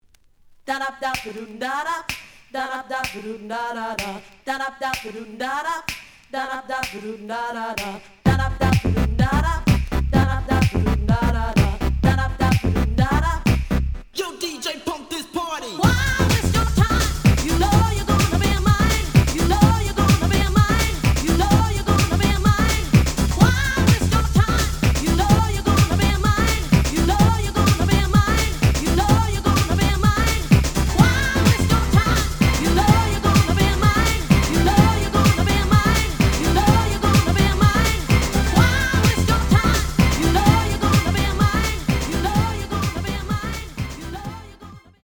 The audio sample is recorded from the actual item.
●Genre: House / Techno
Slight edge warp.